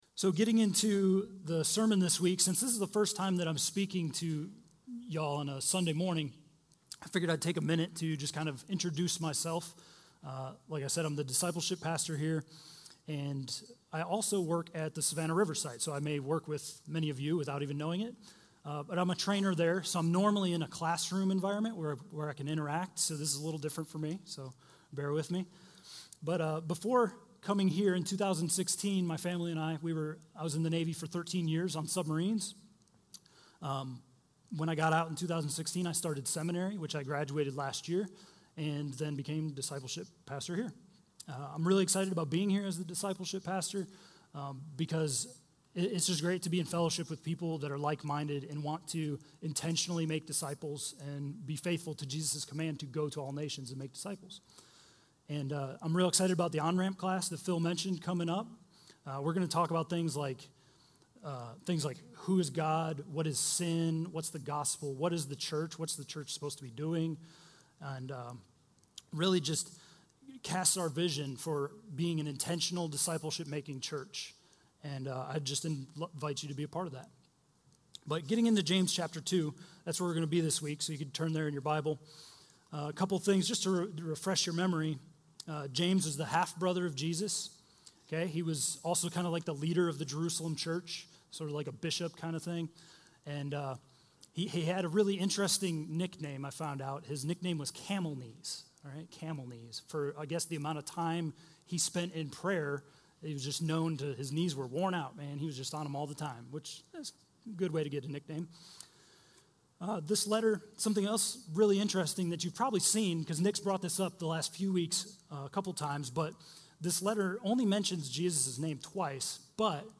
A message from the series "Faith Awakened."